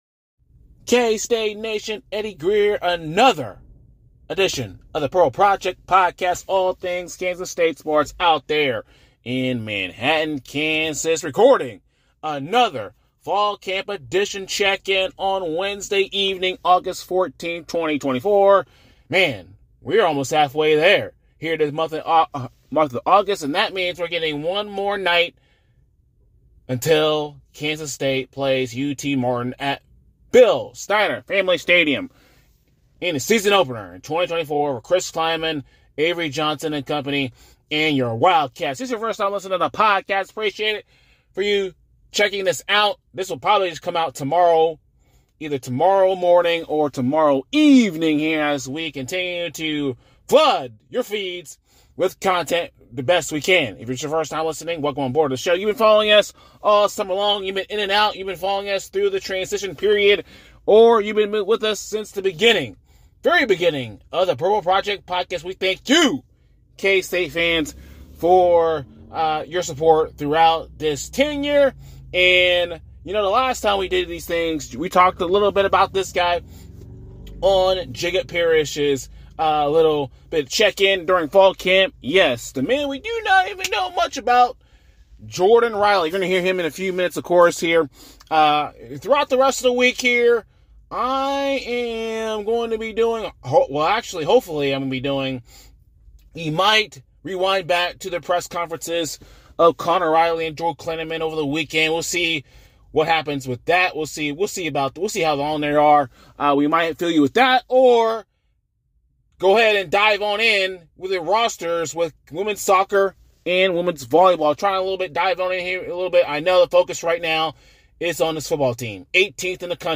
spoke to the media during Fall Camp ahead of the season opener August 31st!